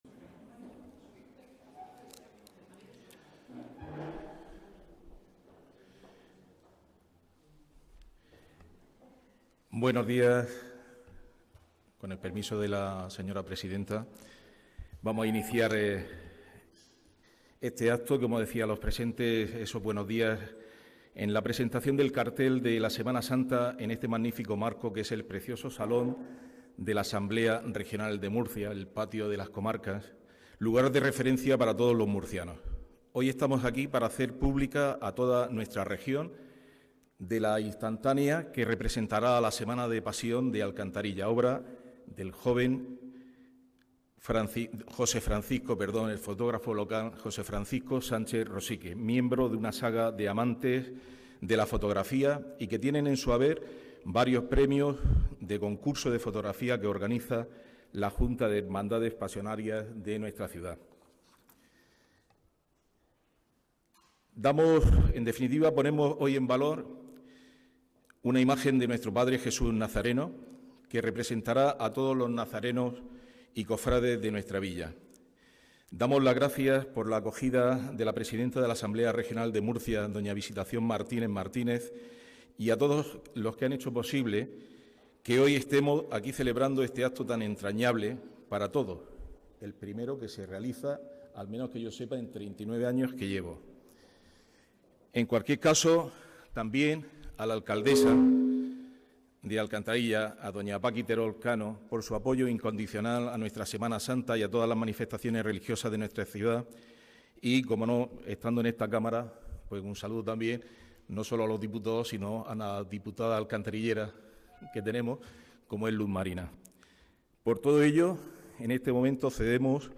La Asamblea Regional acoge la presentación del cartel de la Semana Santa 2026 de Alcantarilla Galería de imágenes en HD Vídeo del acto de presentación del cartel de Semana Santa 2026 de Alcantarilla Audio del acto de presentación del cartel de Semana Santa 2026 de Alcantarilla